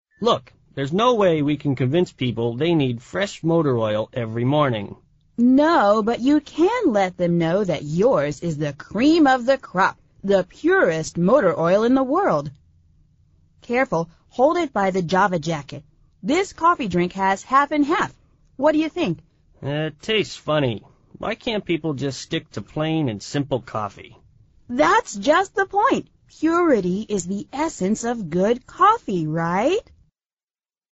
美语会话实录第173期(MP3+文本):It tastes funny